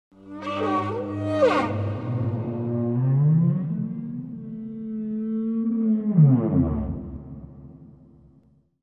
whale-sound